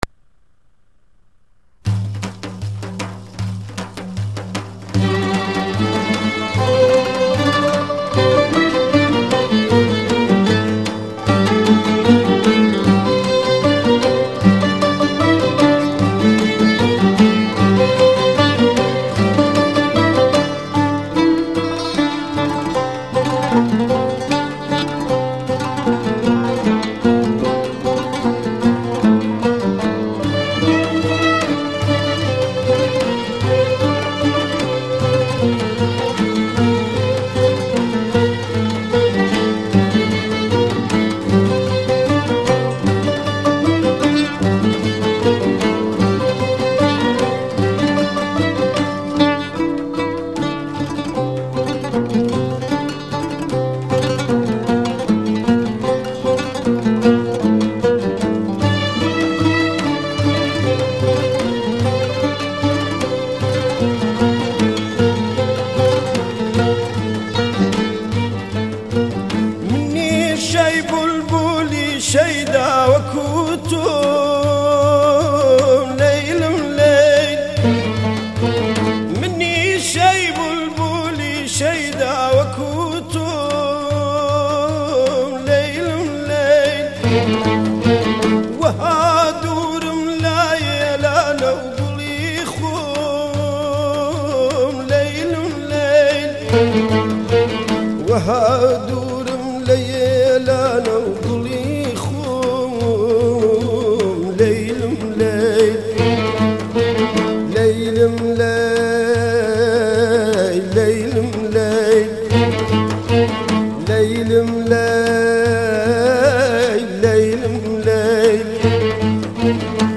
آهنگ کردی